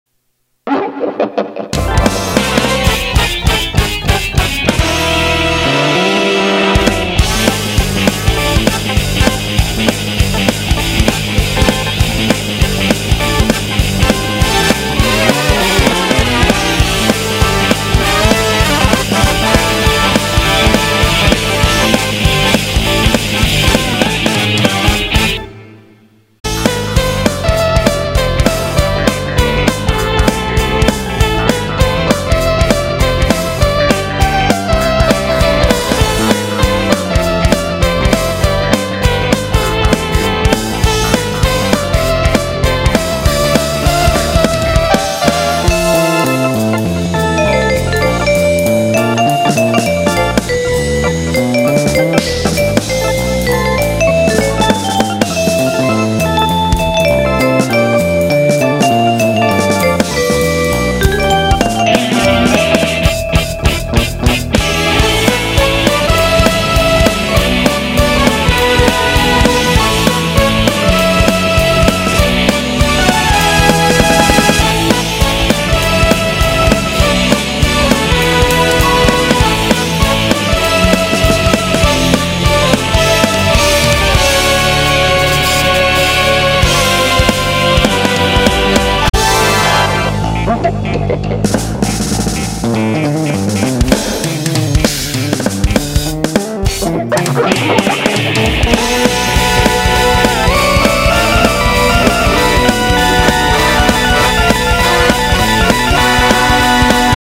作編曲・演奏(Drums,Piano,Keyboards
[Bass,Guitar,etc])：